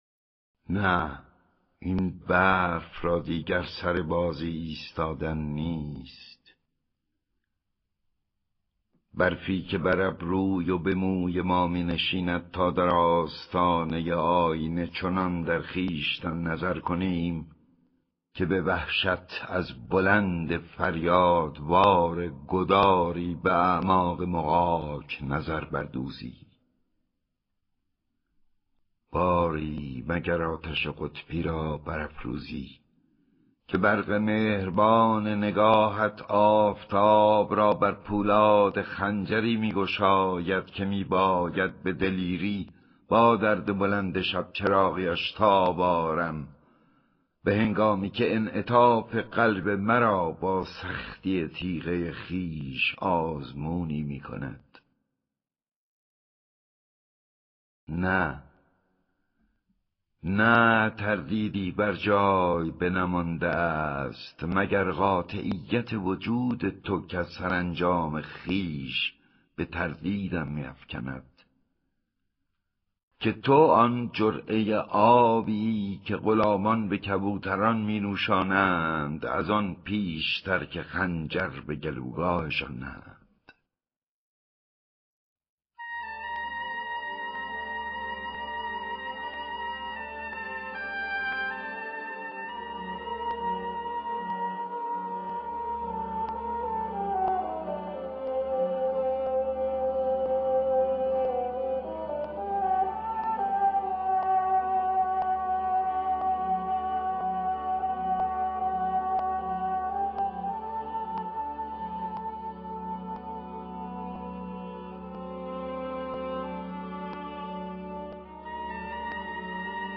دانلود دکلمه با صدای احمد شاملو
گوینده :   [احمد شاملو]